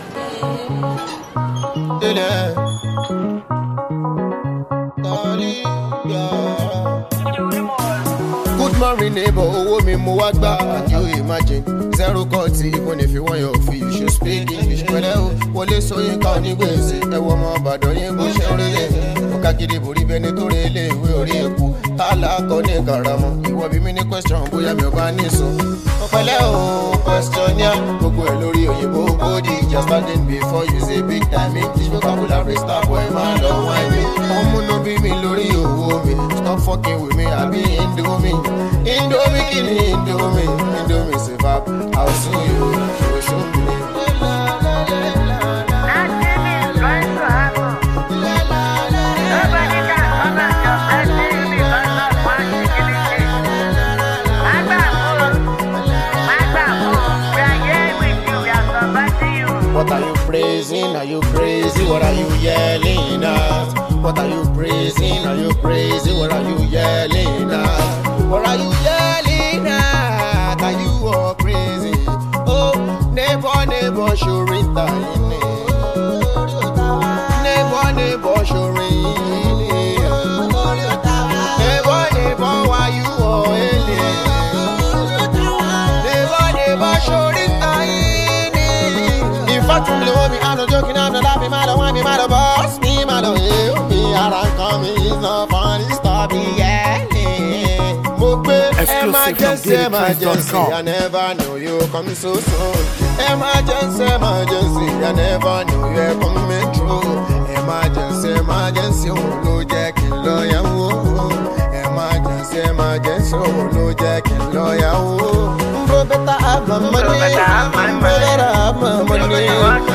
Apala Musician